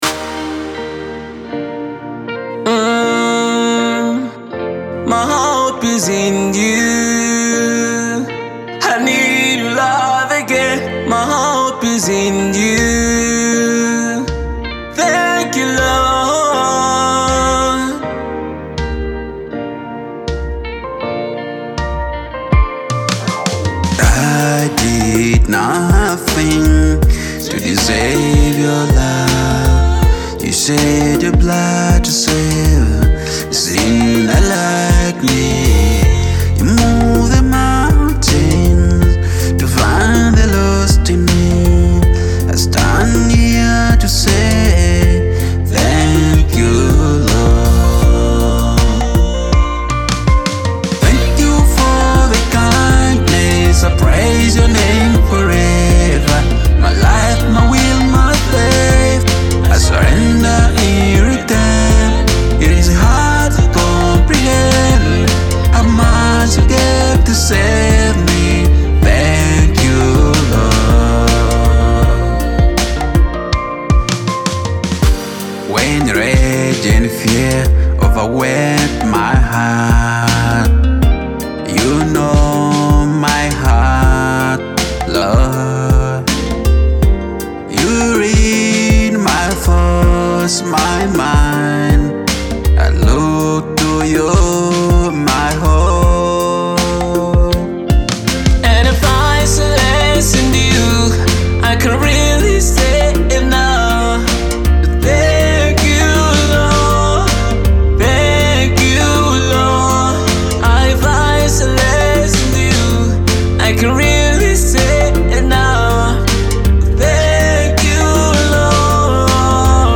Genre: Gospel Music